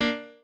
piano4_6.ogg